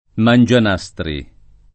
[ man J an #S tri ]